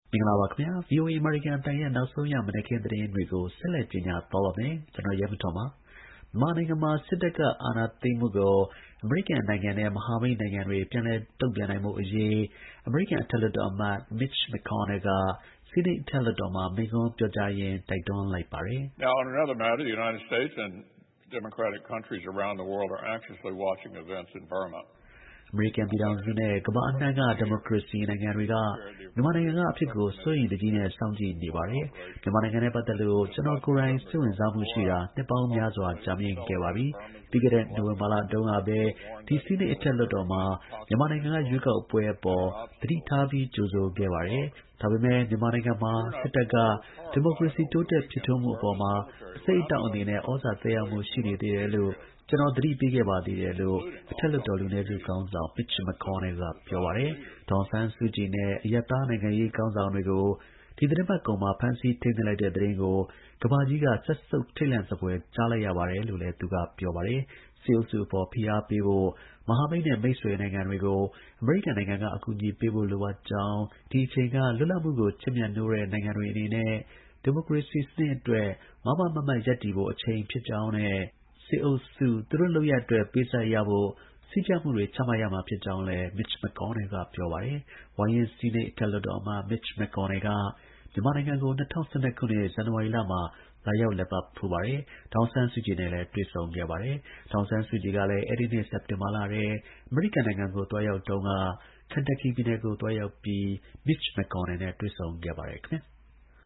မြန်မာနိုင်ငံမှာ စစ်တပ်က အာဏာသိမ်းမှုကို အမေရိကန်နိုင်ငံနဲ့ မဟာမိတ်နိုင်ငံတွေက ပြန်လည် တုံ့ပြန်ဖို့ အမေရိကန်အထက် လွှတ်တော်အမတ် Mitch McConnell က ဆီးနိတ် အထက်လွှတ်တော်မှာ မိန့်ခွန်းပြောကြားရင်း တိုက်တွန်းလိုက်ပါတယ်။